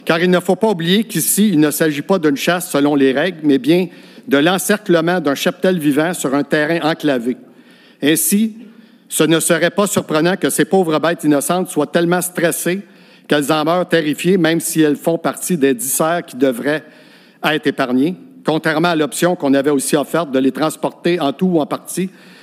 Ils ont été nombreux à prendre la parole lors de la séance publique du conseil mardi soir.